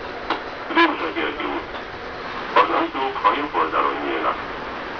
Haltestellenansagen
Budapest (U-Bahn), Dózsa György út (Abfahrt)